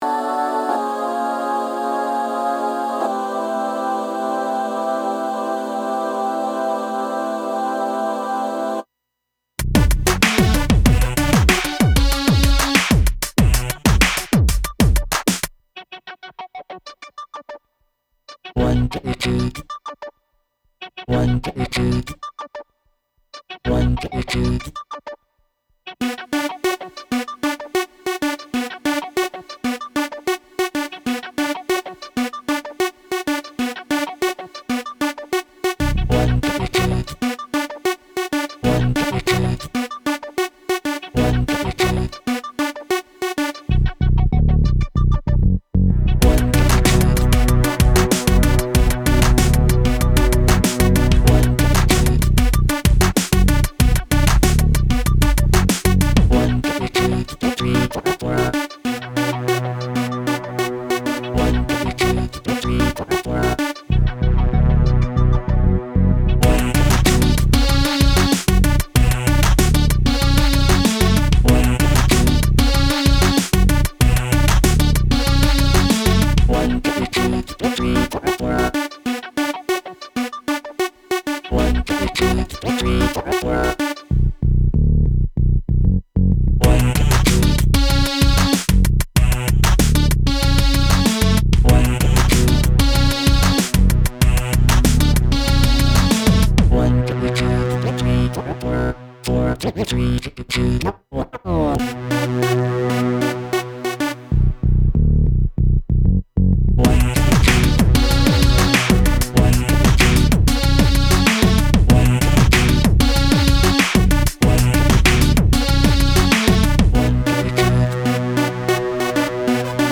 drums, bass, synthesizers, drone, sampled vox